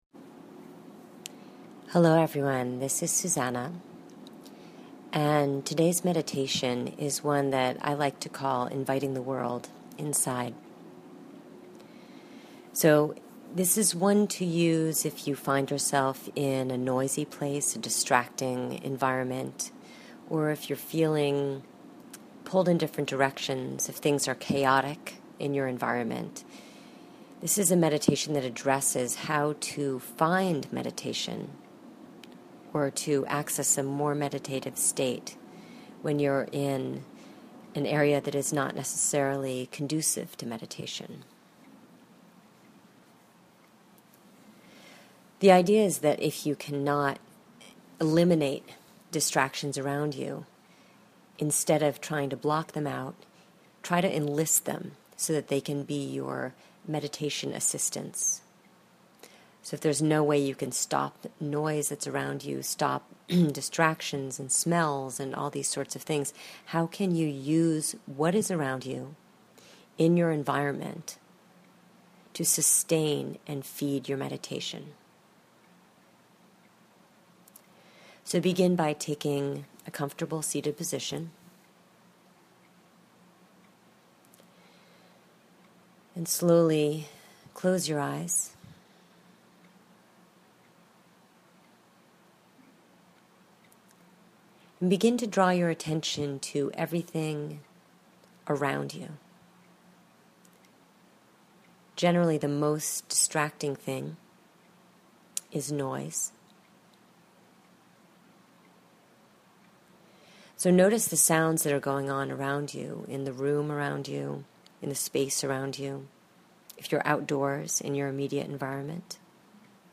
Week 4 Meditation – Inviting the World Inside: